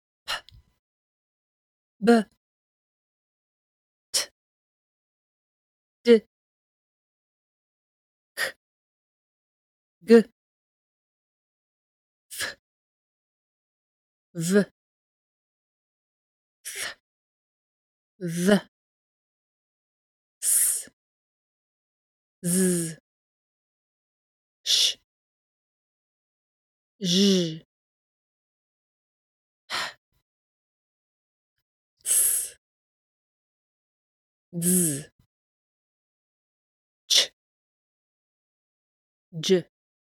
破裂音/p/ /b/ /t/ /d/ /k/ /g/ 摩擦音/f/ /v/ /θ/ /ð/ /s/ /z/ /ʃ/ /ʒ/ /h/ 破擦音/ts/ /dz/ /tʃ/ /dʒ/